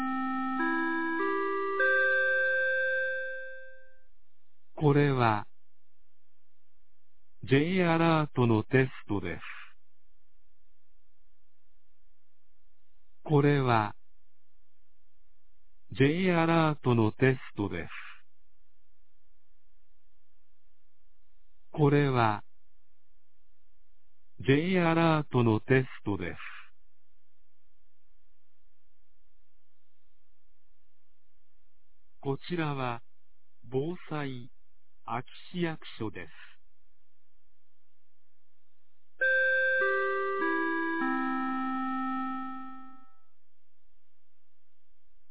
2023年11月15日 11時00分に、安芸市より全地区へ放送がありました。